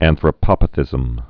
(ănthrə-pŏpə-thĭzəm)